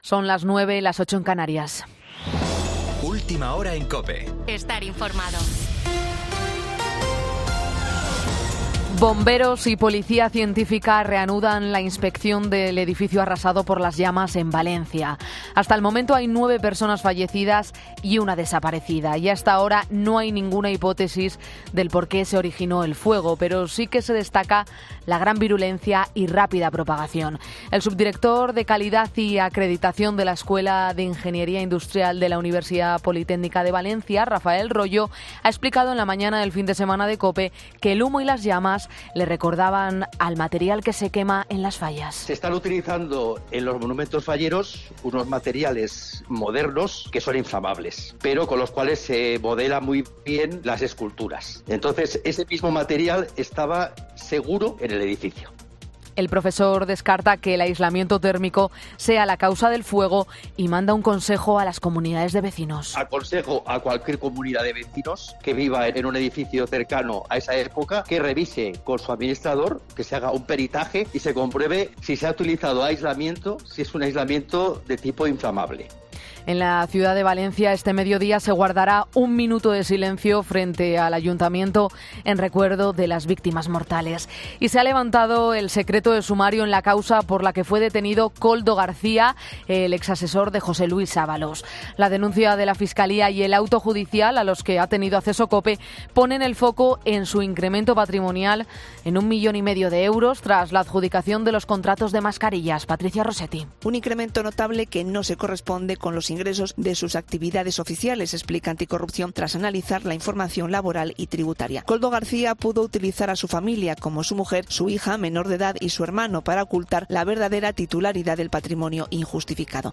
Boletín 09.00 horas del 24 de febrero de 2024